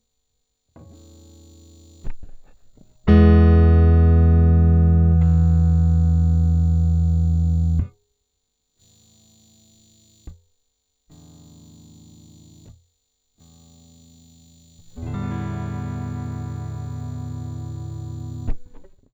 Allez, dans la foulée j'ai récupéré une AR-133, test guit-->AR-133--> entrée carte son symétrique...
Et pas de changement. Écoute au casque pour ne pas passer par les enceintes, le parasite est toujours là.
Vu d'ici, il se pourrait que l'onduleur ne soit pas en cause ; la pollution n'est pas continue et est de très faible amplitude.
Dès que je relâche les cordes, le son réapparait. A noter qu'il est aussi présent en fond lorsque je bloque les cordes.
noise-test.wav